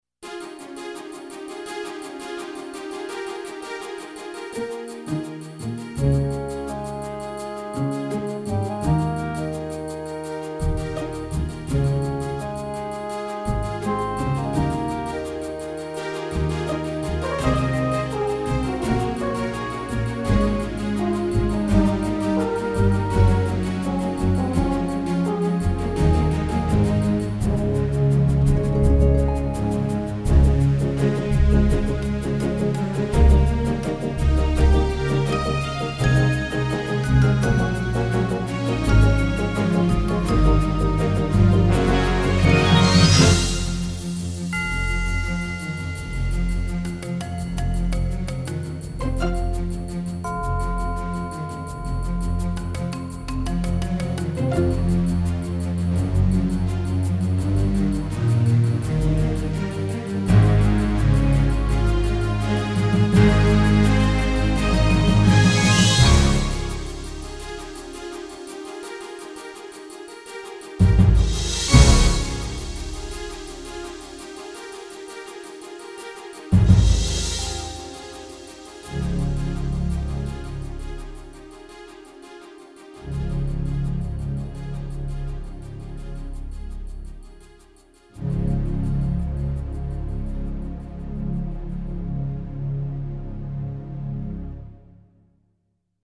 ALESIS NanoSynth Demo